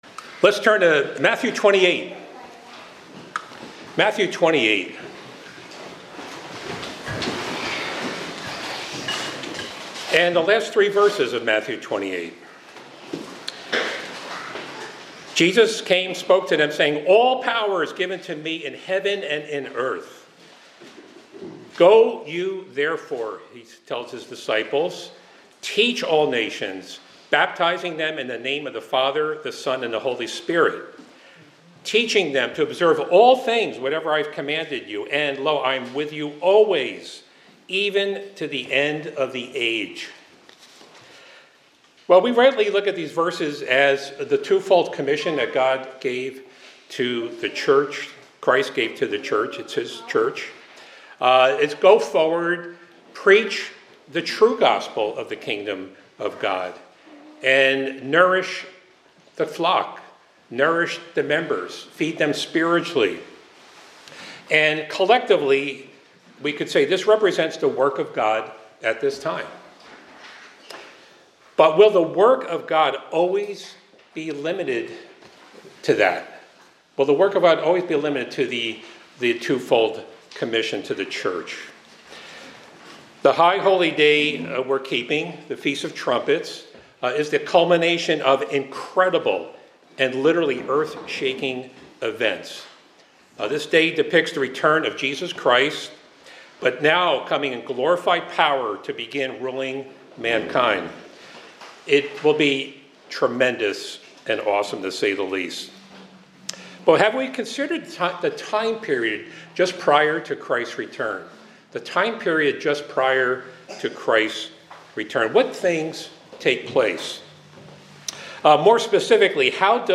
This sermon explores the work of God during the end times, focusing on the period from the start of the tribulation until the return of Jesus Christ. It emphasizes that God's work continues beyond the traditional commission given to the church, highlighting prophetic events and the roles of specific servants of God during this tumultuous era.